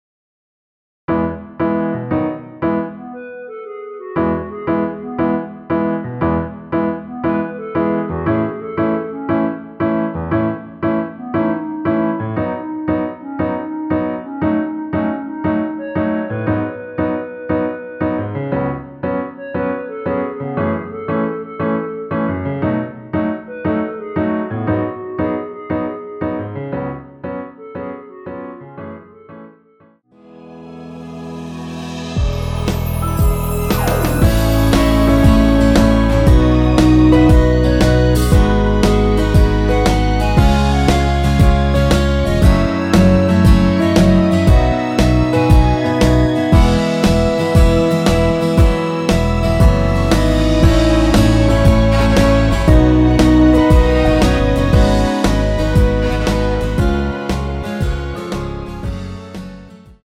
전주 없이 시작하는 곡이라 전주 만들어 놓았습니다.(미리듣기 참조)
원키에서(+3)올린 멜로디 포함된 MR입니다.
앞부분30초, 뒷부분30초씩 편집해서 올려 드리고 있습니다.